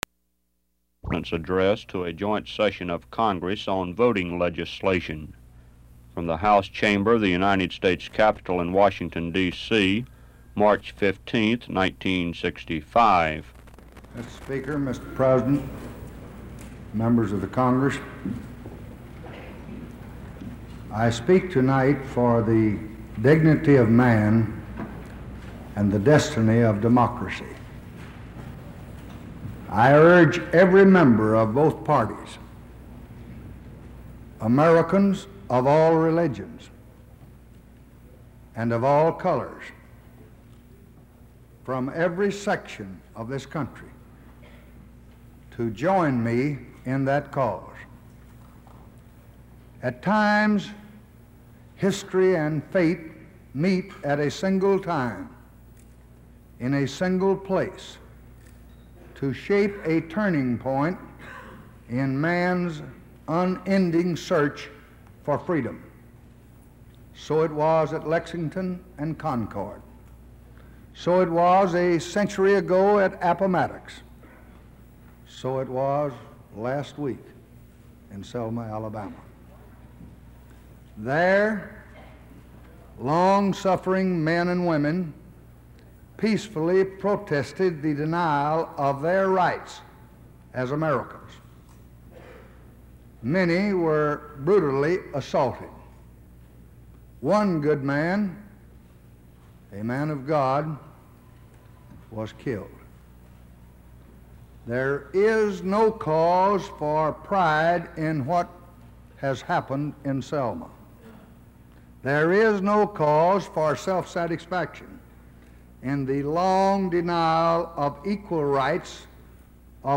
March 15, 1965: Speech Before Congress on Voting Rights